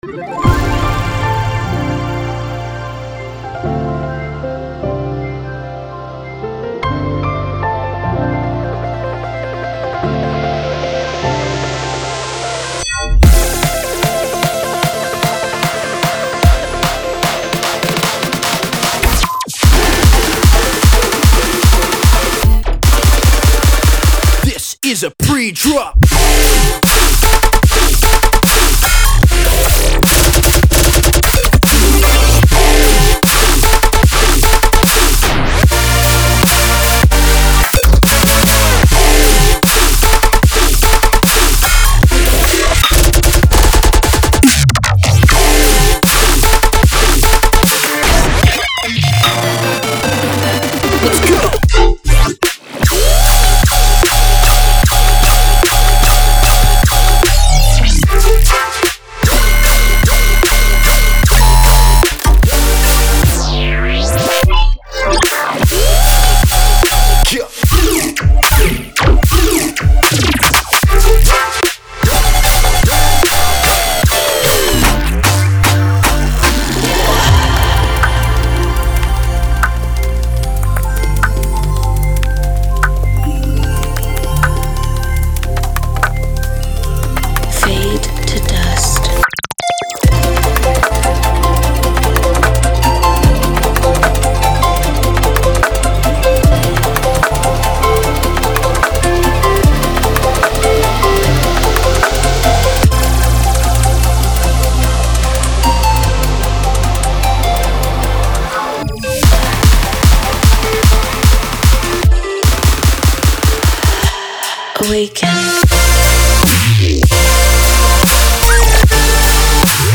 所有音色都经过预处理和优化，随时准备为你的音乐注入缤纷的色彩。